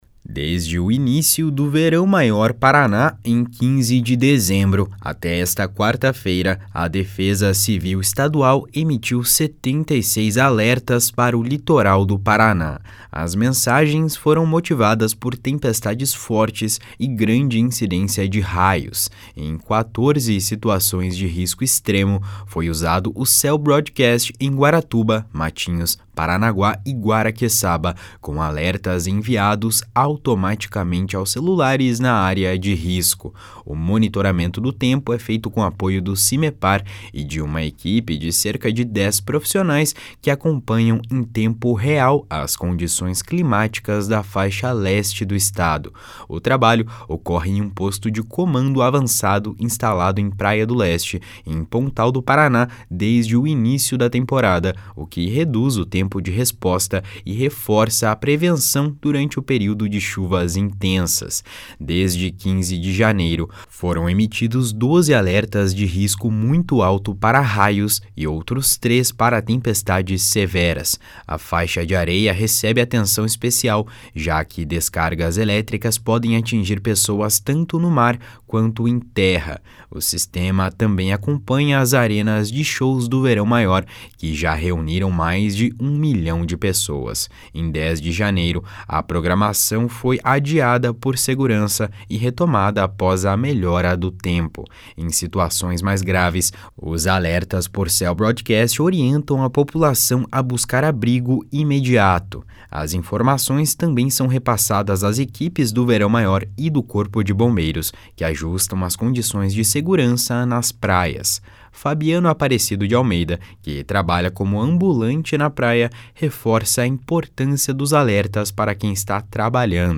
(Repórter